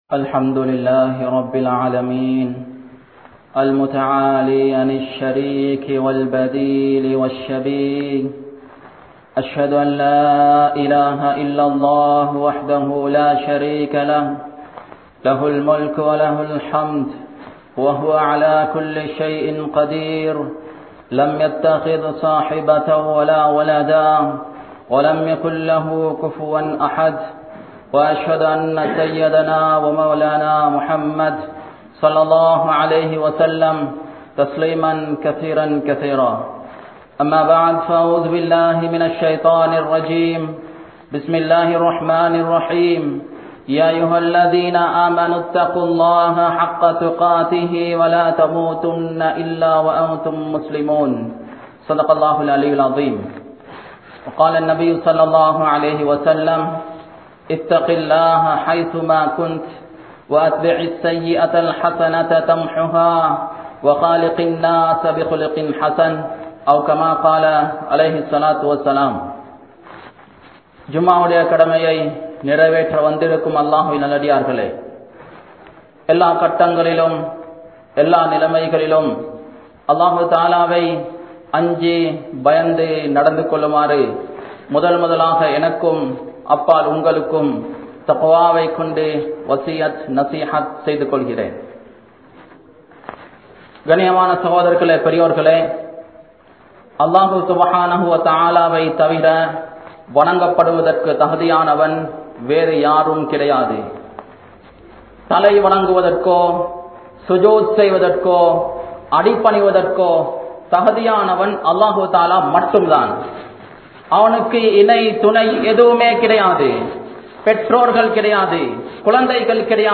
Noanpu Kadamaiyaakkapatathan Noakkam (நோன்பு கடமையாக்கப்பட்டதன் நோக்கம்) | Audio Bayans | All Ceylon Muslim Youth Community | Addalaichenai
Jamiul Azhar Jumua Masjidh